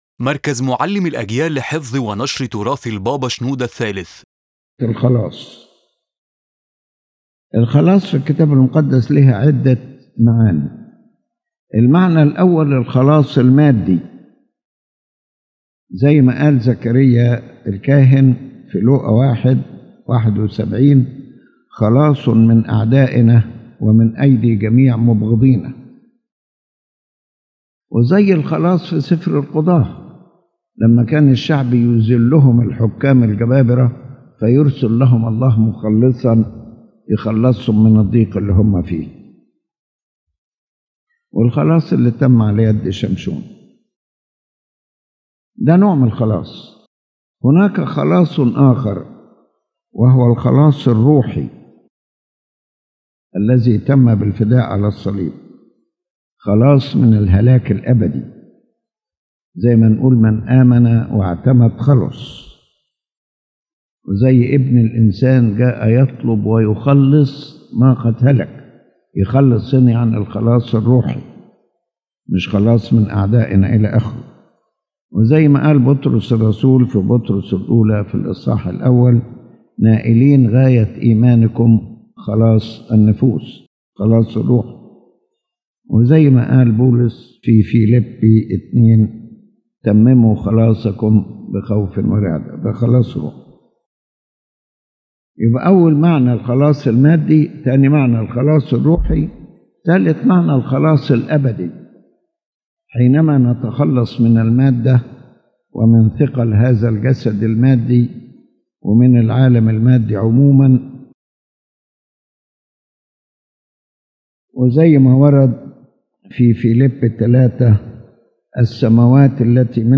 His Holiness Pope Shenouda III explains that the word salvation in the Holy Bible has several meanings, each appearing in a different context. Salvation is not a single concept, but stages and levels that all lead to God’s final salvation through redemption.